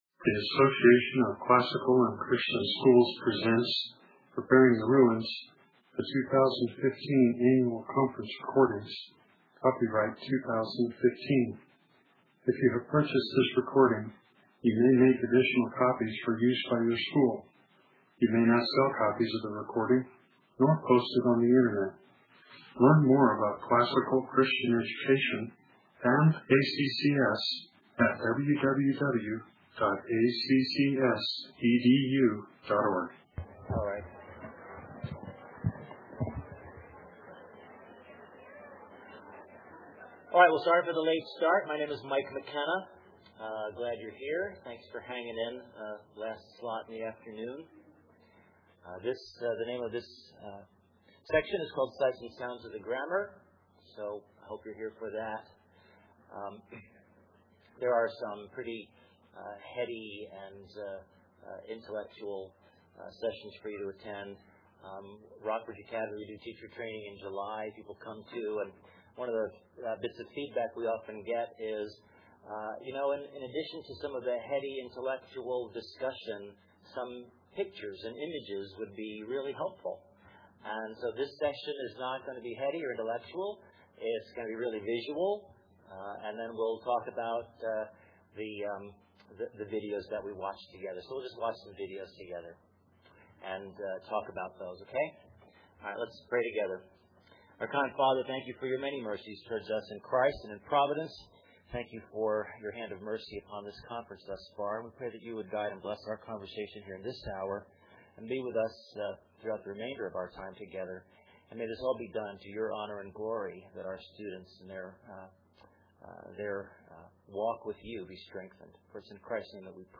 2015 Practicum Talk, 1:00:38, K-6, General Classroom, Virtue, Character, Discipline